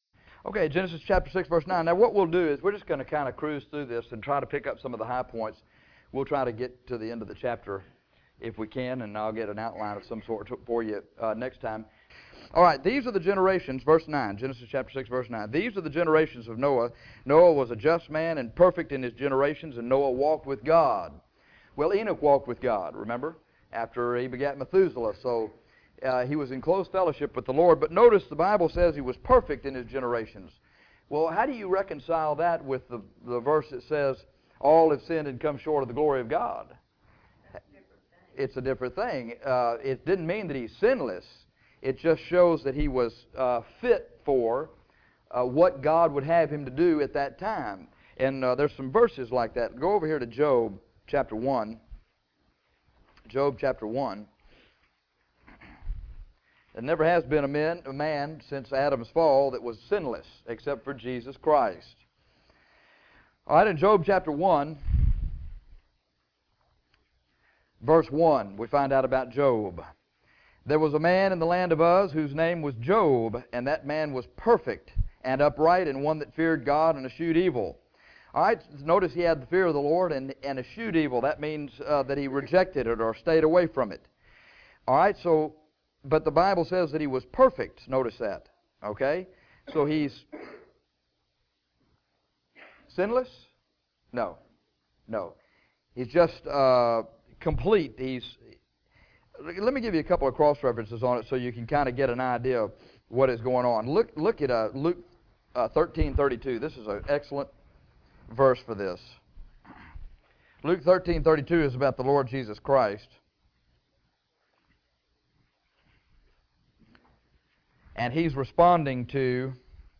In this lesson, we will study Noah’s family, the corruption that was in the earth, the construction and typology of the ark, and the animals that were spared.